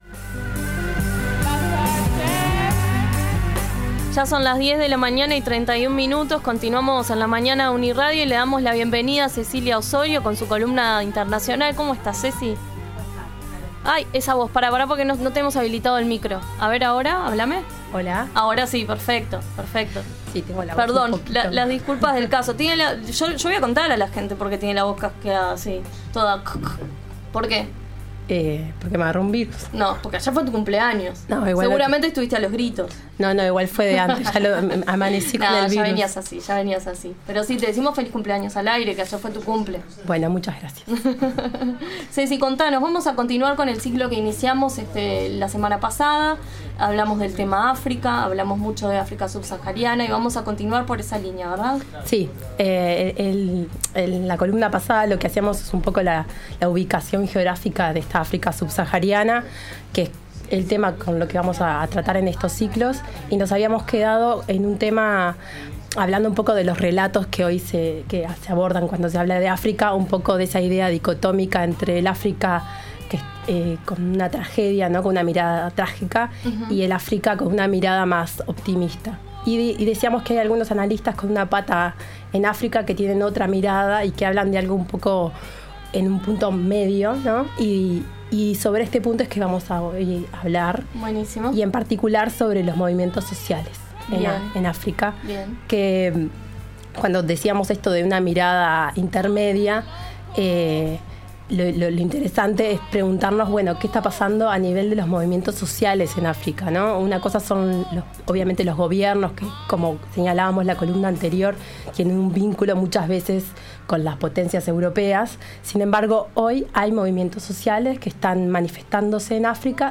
Segunda columna de análisis internacional en La Mañana de UNI Radio. En esta ocasión abordamos los movimientos sociales en África Subsahariana, particularmente las llamadas «Primaveras Africanas».